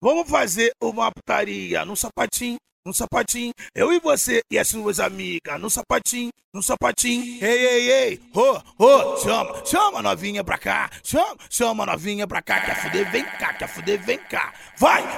Acapella de Funk